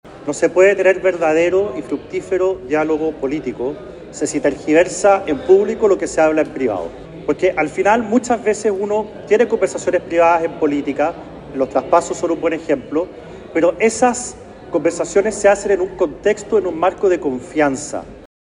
Ante las críticas, el diputado y presidente de la UDI, Guillermo Ramírez, afirmó que no se puede tener conversaciones fructíferas si se rompen códigos y hay desconfianza de por medio.